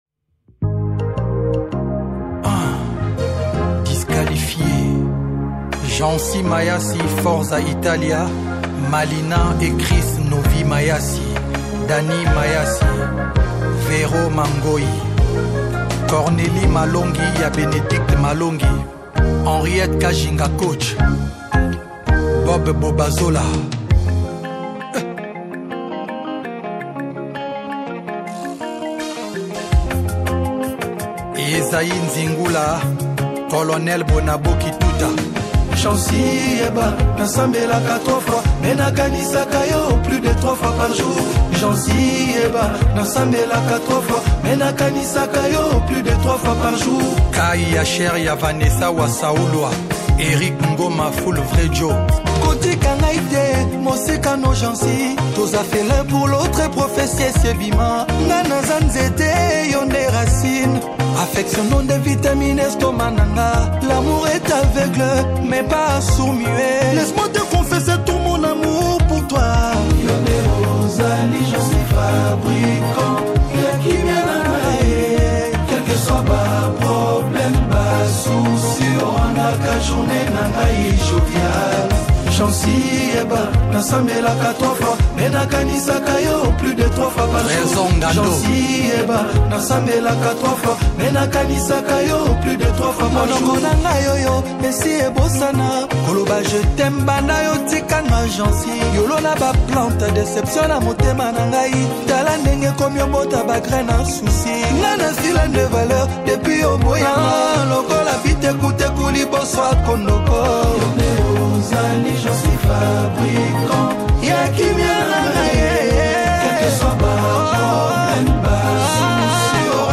| Rumba